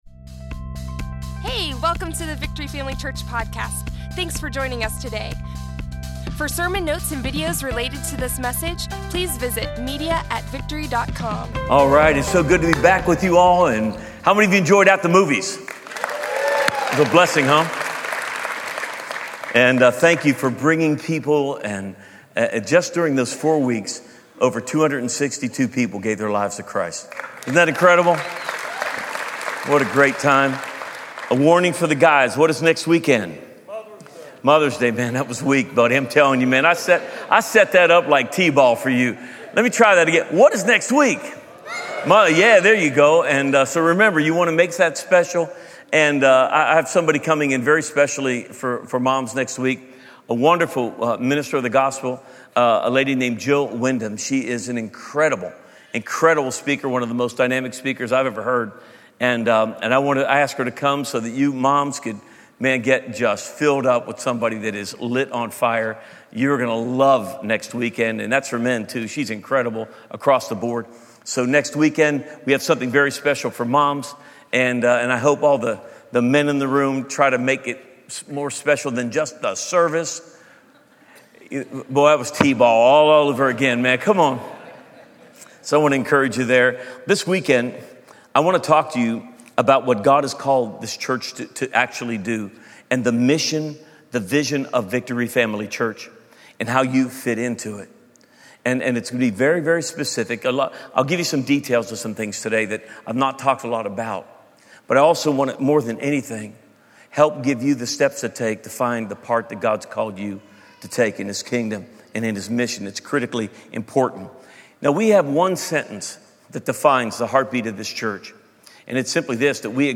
Access sermon video, audio, and notes from Victory Family Church online today!